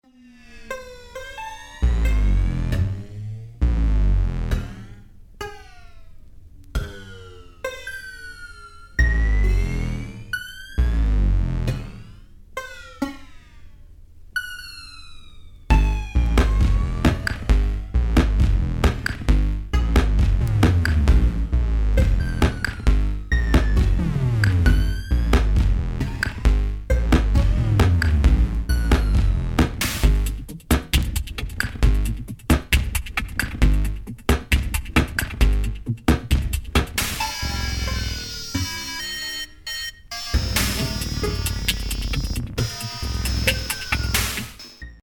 from more experimental textures